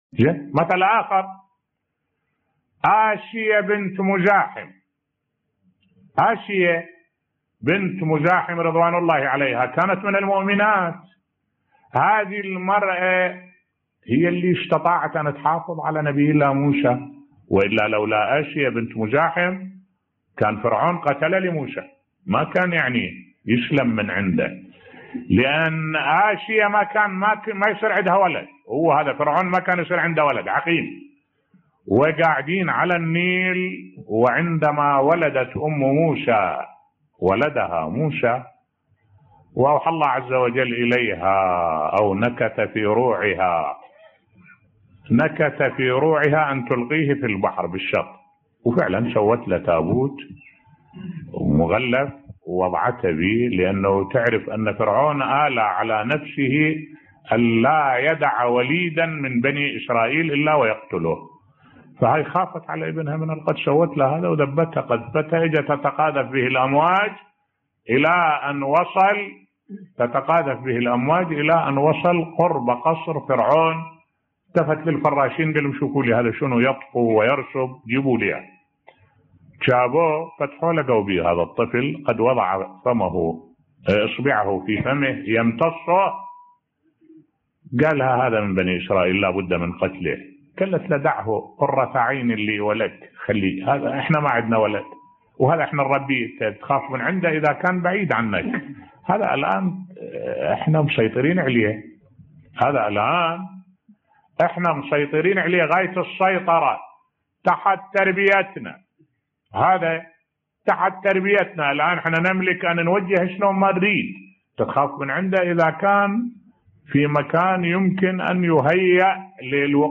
ملف صوتی تأثير زوجة فرعون على فرعون بصوت الشيخ الدكتور أحمد الوائلي